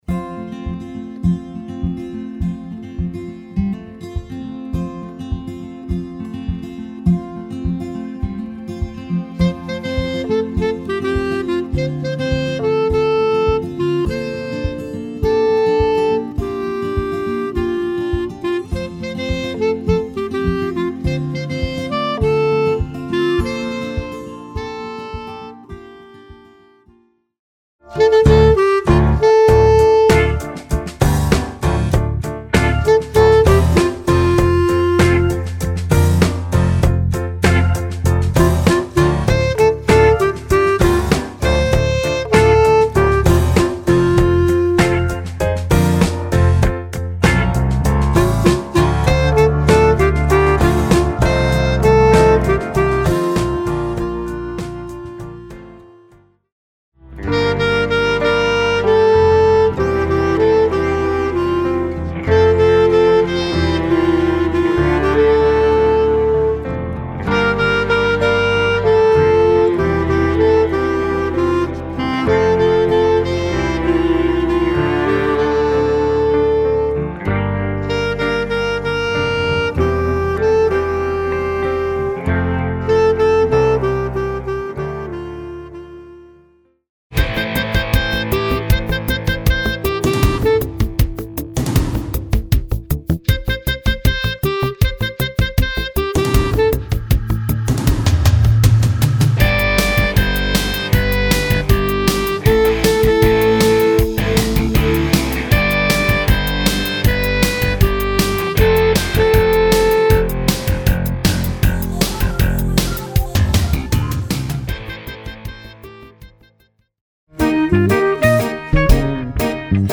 Voicing: Clarinet